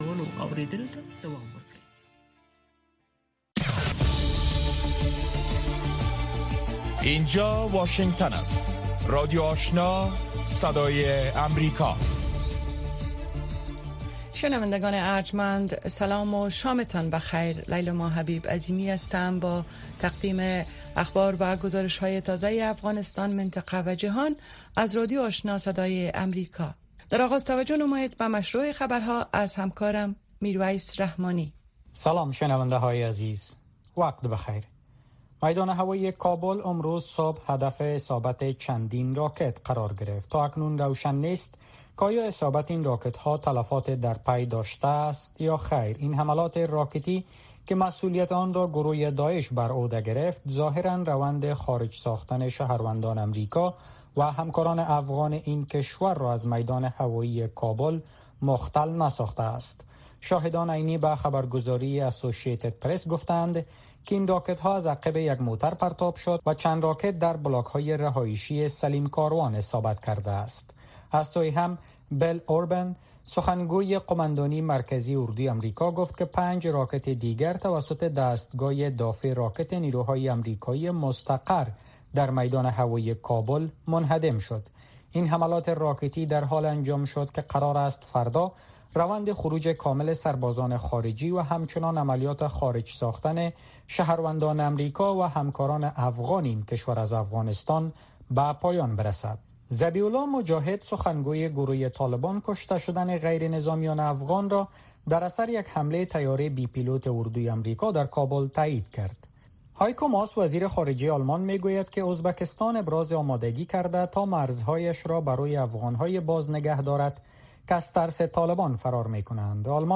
نخستین برنامه خبری شب
در برنامه خبری شامگاهی، خبرهای تازه و گزارش‌های دقیق از سرتاسر افغانستان، منطقه و جهان فقط در نیم ساعت پیشکش می‌شود.